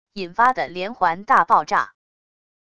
引发的连环大爆炸wav音频